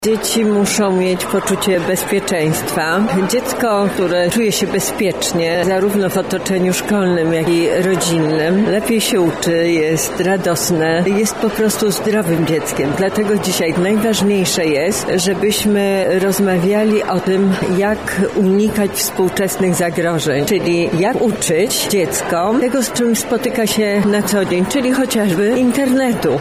Dzisiaj, 28 stycznia, w Szkole Podstawowej nr 52 im. Marii Konopnickiej w Lublinie odbyła się konferencja „Szkoła w centrum zmian” poświęcona współczesnym problemom, z którymi zmagają się dyrektorzy i nauczyciele.
Szczególną wartością wydarzenia było wystąpienie Pani Sędzi Anny Marii Wesołowskiej, będącej autorytetem w obszarze ochrony praw dzieci i młodzieży: